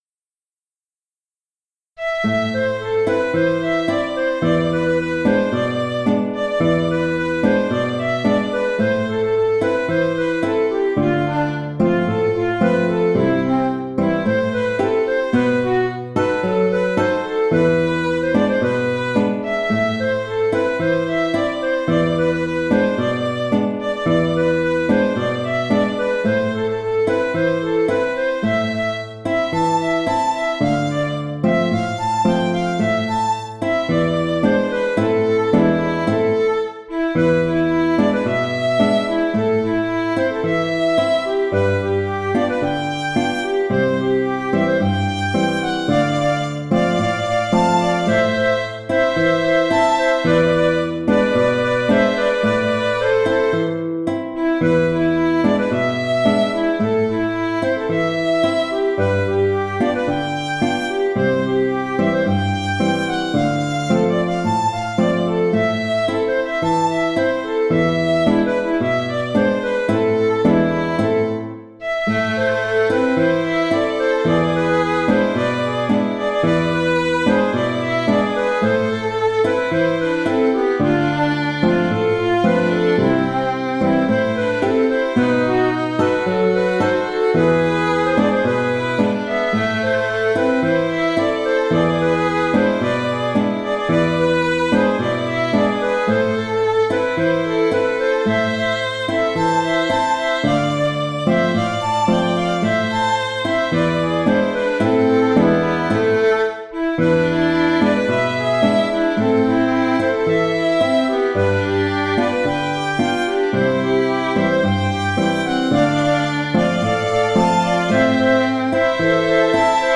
< Retour Autres musiques Bando Argentino Tango Auteur
L’enregistrement en sons numériques ne restitue évidemment pas l’esprit musical du tango.
J’ai écrit cet arrangement en utilisant le rythme de base classique du tango.